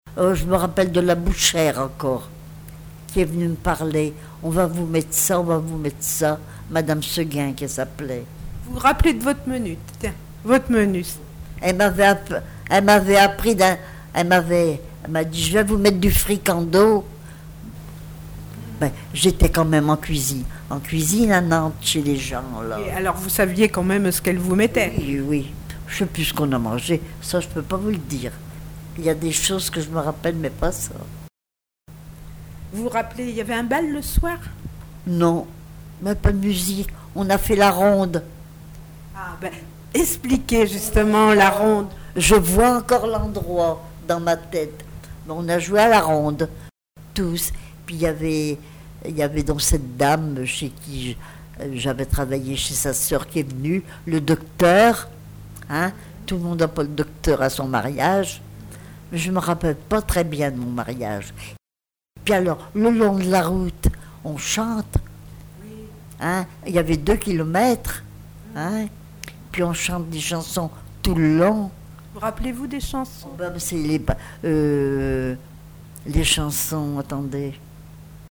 témoignages sur le vécu de l'interviewée
Catégorie Témoignage